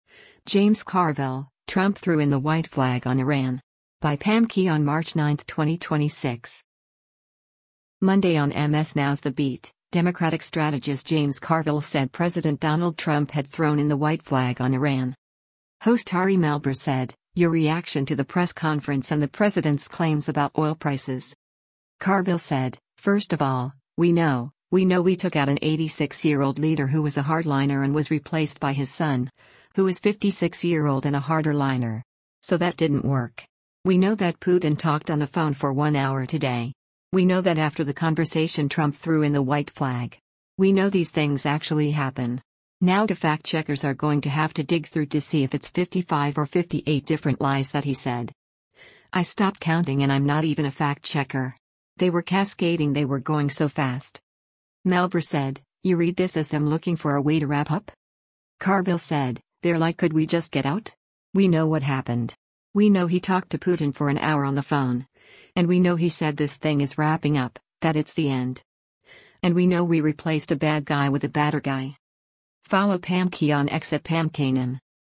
Monday on MS NOW’s “The Beat,” Democratic strategist James Carville said President Donald Trump had thrown in the “white flag” on Iran.